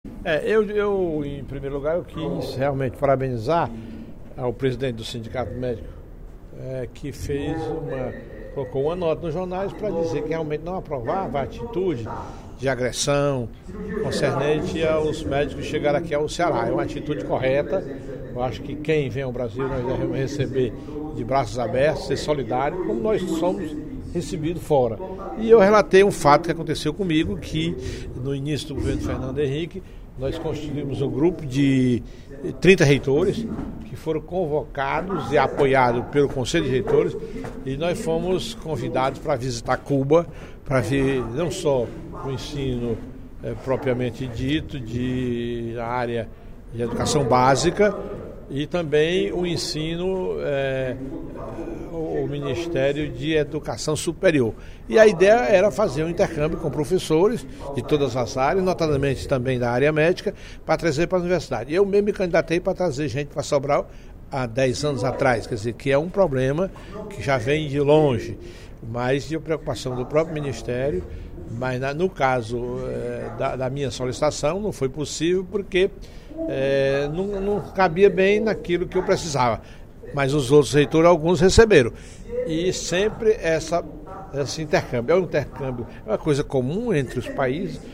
O deputado Professor Teodoro (PSD) condenou, em pronunciamento no primeiro expediente da sessão plenária desta sexta-feira (30/08), a hostilidade contra os médicos cubanos que chegaram a Fortaleza para o programa Mais Médicos.